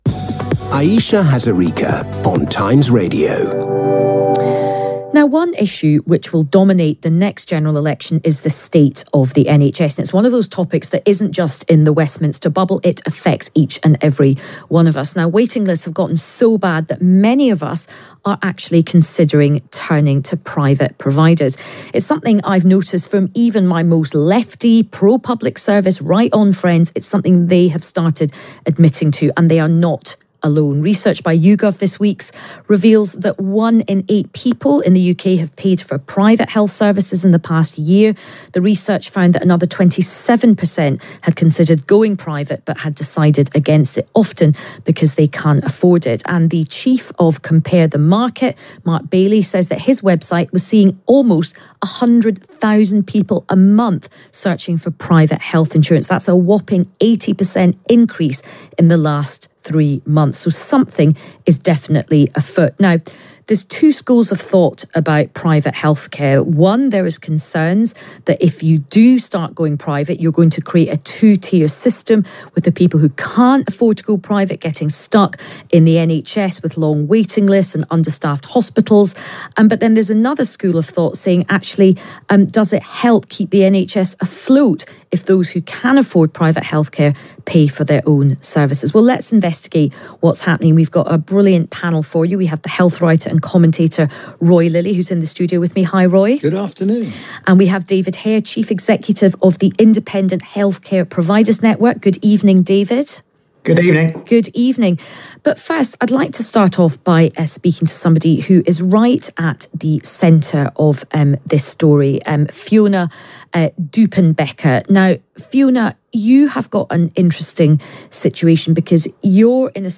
22 April 2023 Times Radio Panel Debate – Private Healthcare